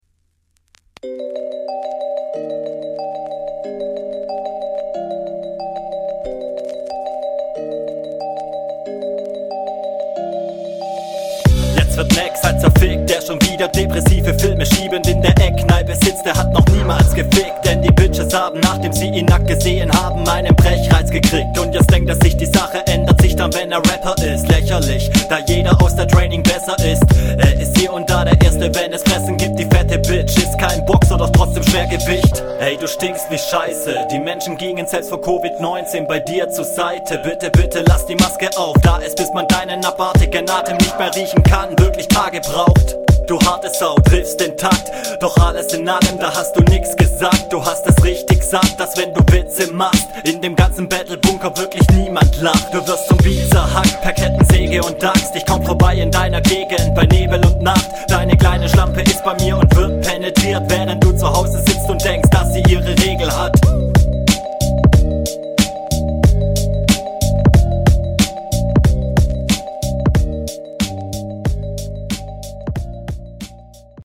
Der Beat liegt dir schon eher, wirkst viel präsenter, wieder schöne Flowpatterns und Reimstrukturen, auch …
Flow und Stimme kommt auf solchen Beats natürlich viel besser.